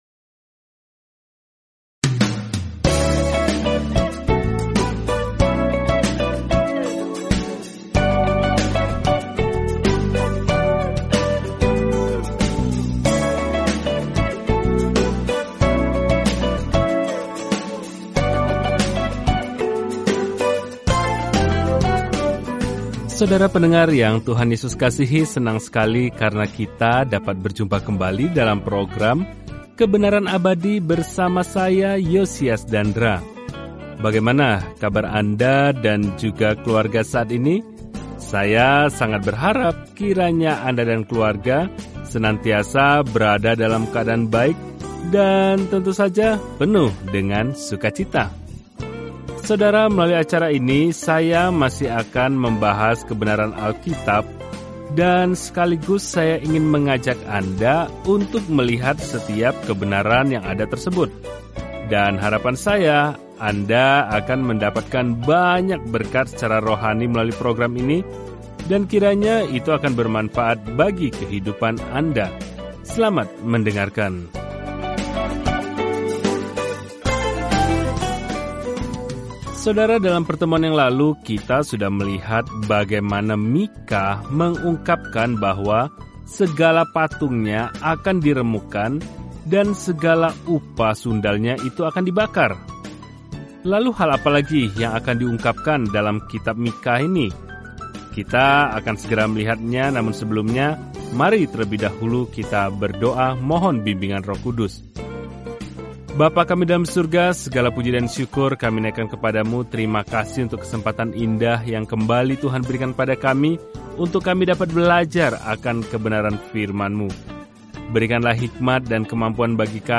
Telusuri 2 Yohanes setiap hari sambil mendengarkan pelajaran audio dan membaca ayat-ayat tertentu dari firman Tuhan.